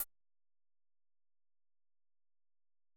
UI Click 3.wav